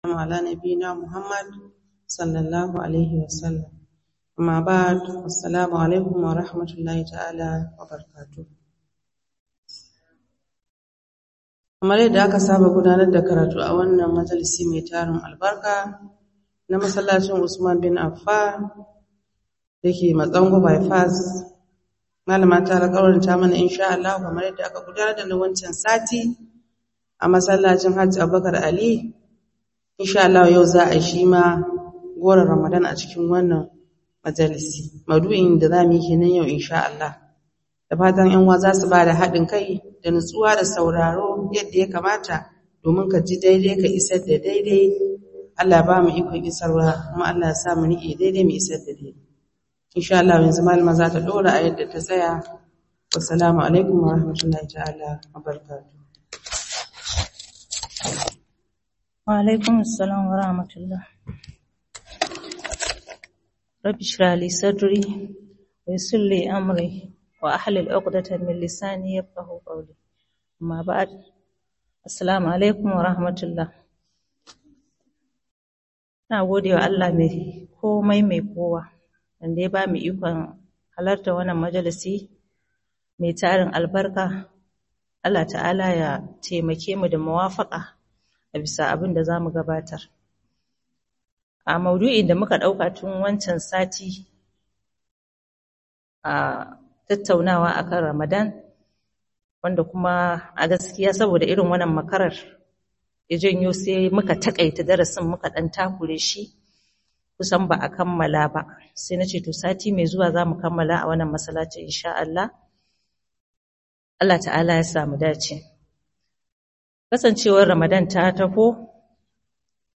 Maraba Da Ramadan 2 - Muhadara